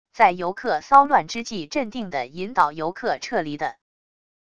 在游客骚乱之际镇定地引导游客撤离的wav音频